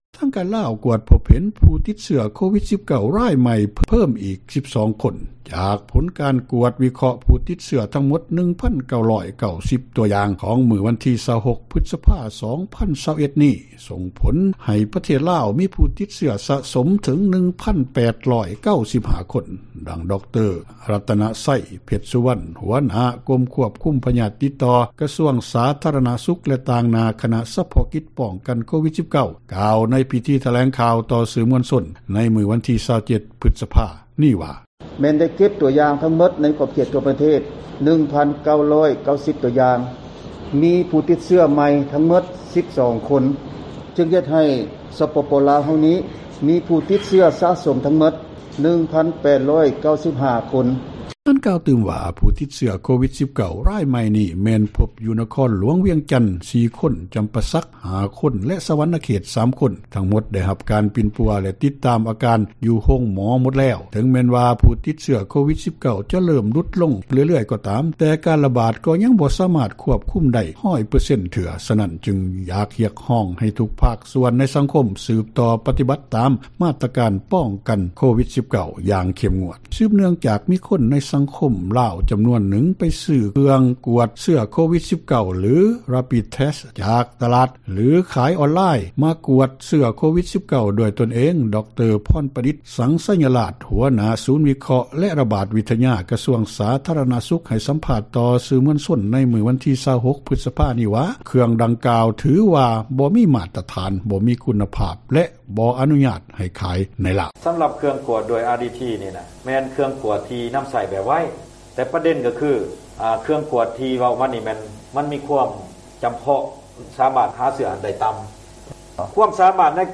ດັ່ງ ດຣ ລັດຕະນະໄຊ ເພັດສຸວັນ ຫົວຫນ້າກົມຄວບຄຸມພຍາດຕິດຕໍ່ ກະຊວງສາທາຣະນະສຸຂ ຕ່າງຫນ້າຄະນະສະເພາະກິຈຕ້ານ ໂຄວິດ-19 ກ່າວຕໍ່ສື່ມວນຊົນ ໃນພິທີຖແລງຂ່າວ ໃນມື້ວັນທີ 27 ພຶສພາ ນີ້ວ່າ: